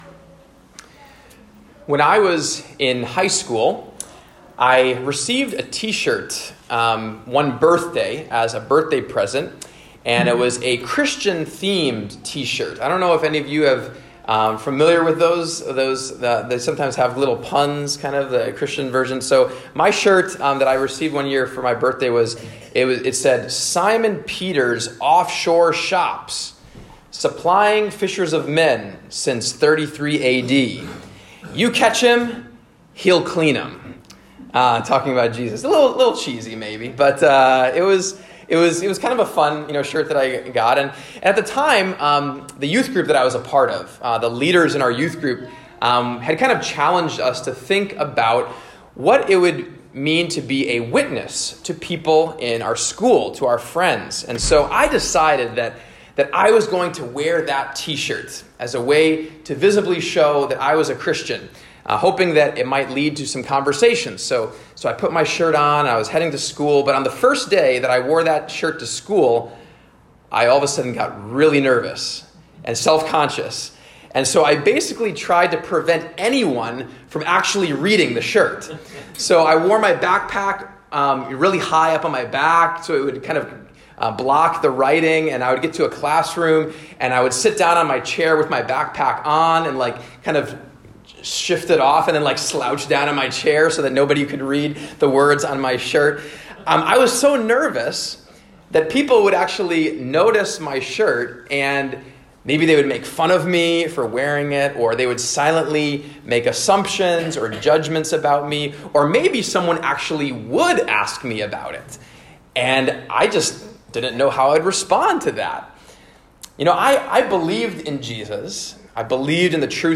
"Confident Witness" The third message in our "Witnesses" sermon series, looking at Acts 4:1-22, where Peter and John display a confident witness in the face of opposition. We explore how to have a confident witness in our lives.